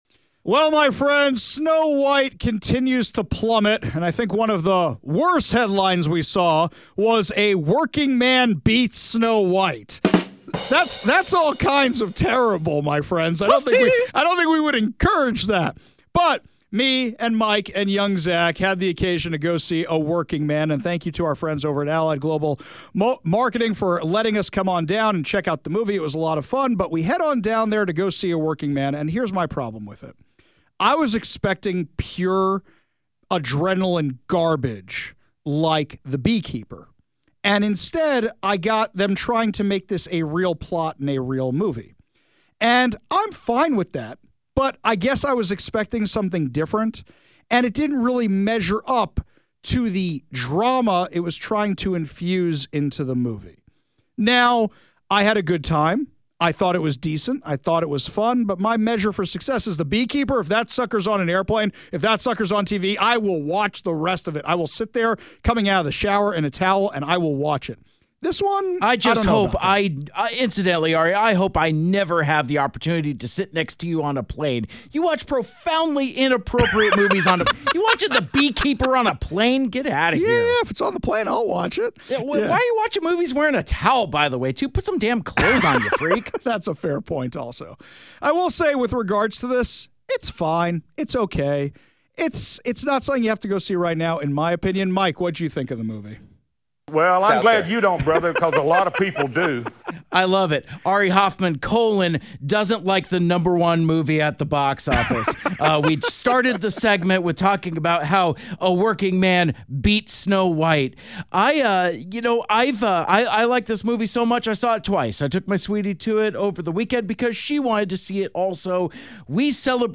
a-working-man-review.mp3